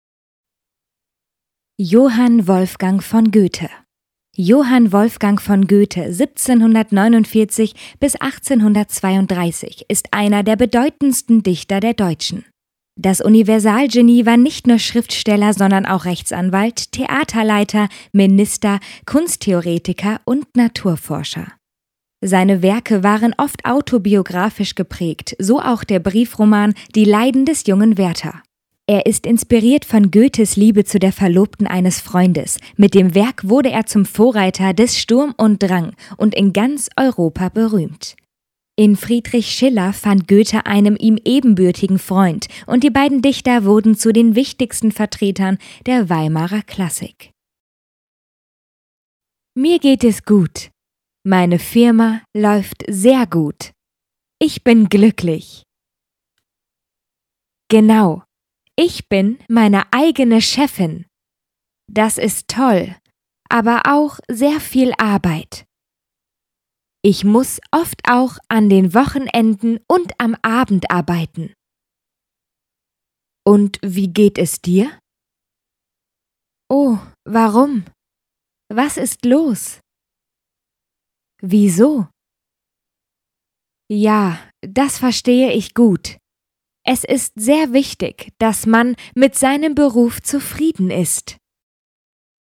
配音风格： 大气 浑厚 舒缓